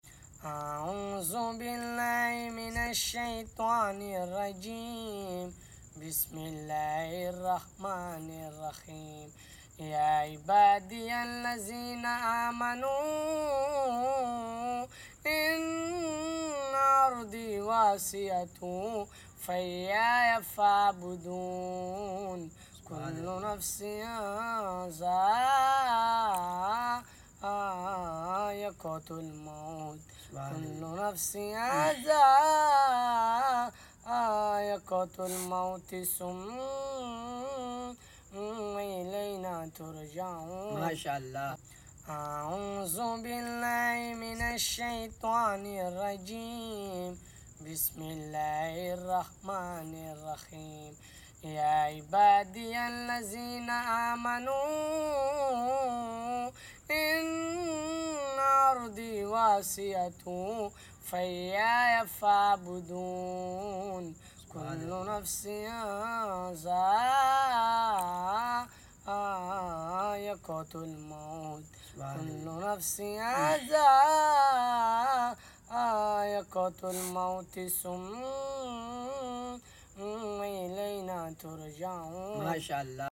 Mashallah Tilawat e Quran Pak